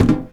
metal_tin_impacts_deep_05.wav